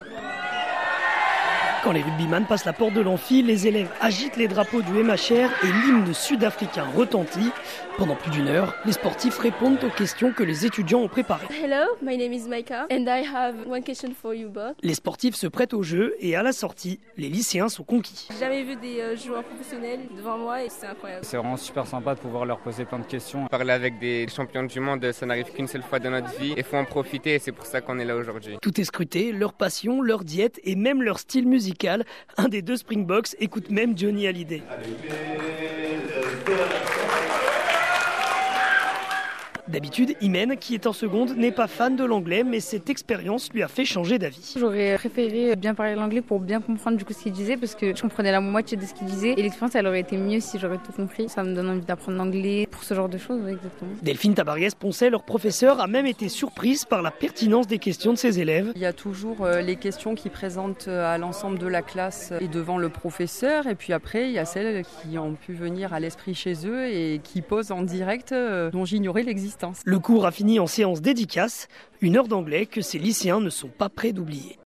• Interview d'Ici Hérault